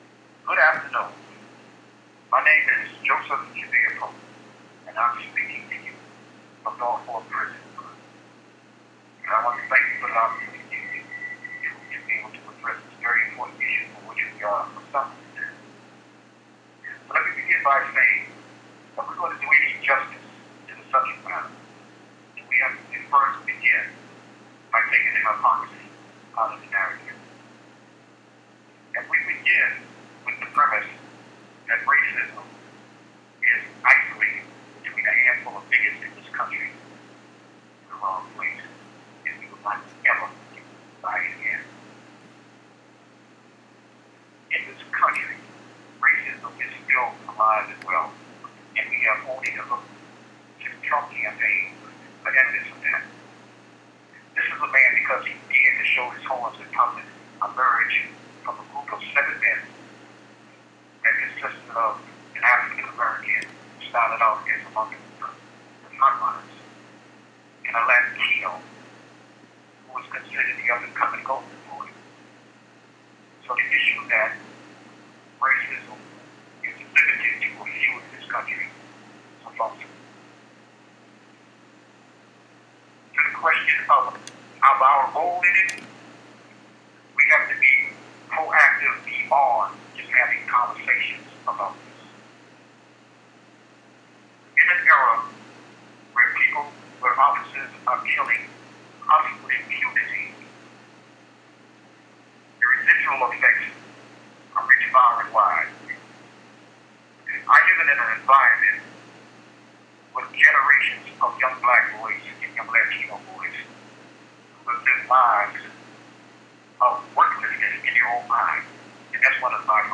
Unfortunately the technology at the speak out did not work to be able to share his recorded remarks, so I wanted to share them here.